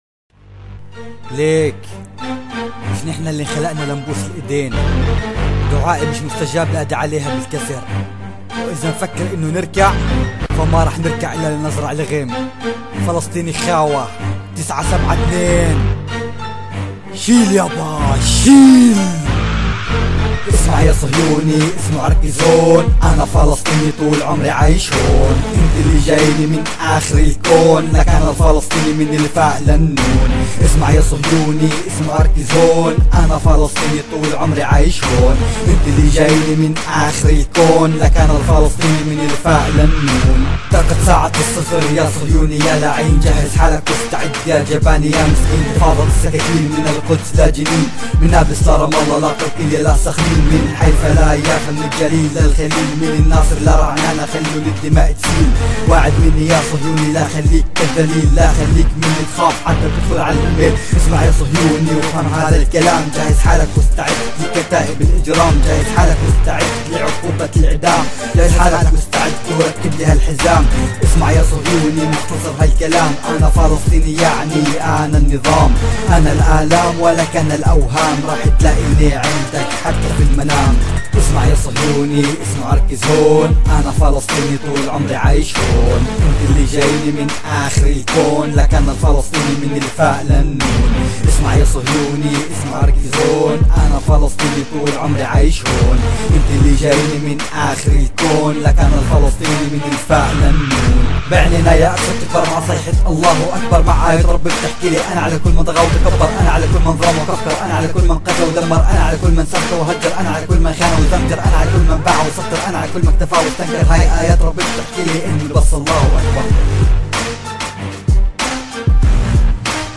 اقوي راب فلسطيني جديد 2016